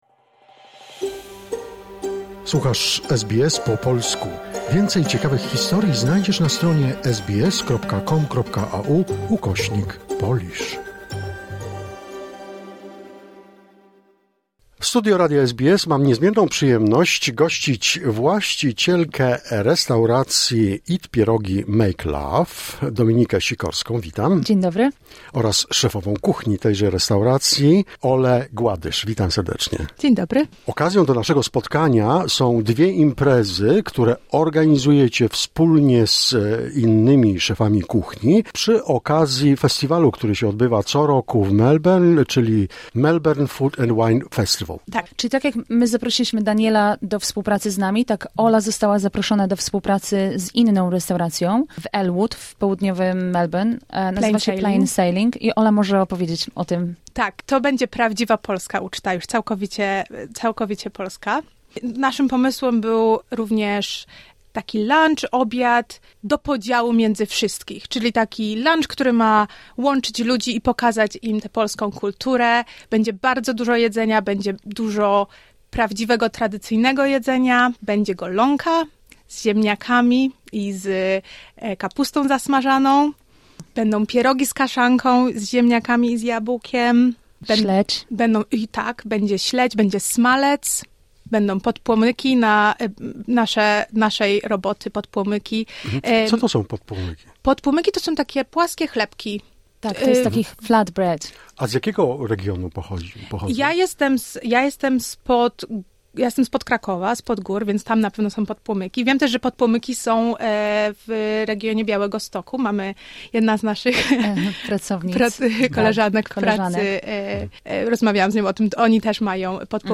Ciąg dalszy rozmowy